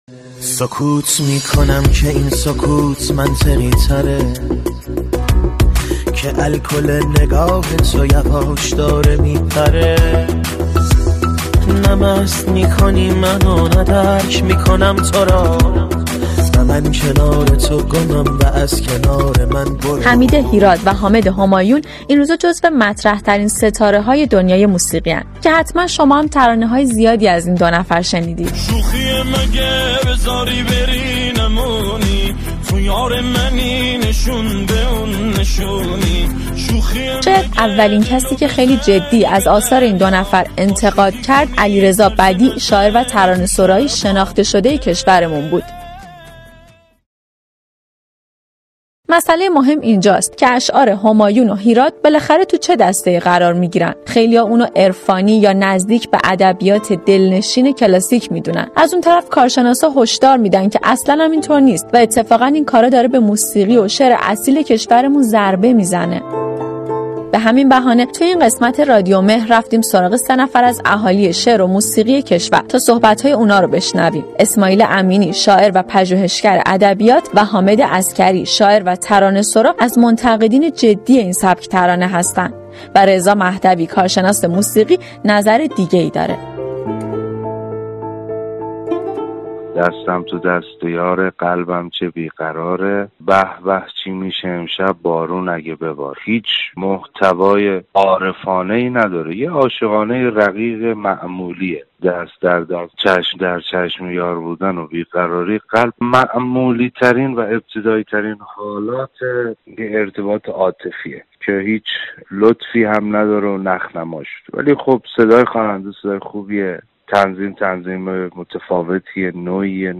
ترانه‌های«حامد همایون» و«حمید هیراد» این روزها نظرات موافق ومخالف زیادی دارند: عرفانی و کلاسیک یا سطحی ومعمولی؟!در این قسمت رادیومهر با سه نفر ازاهالی موسیقی درباره این آثار صحبت کرده ایم.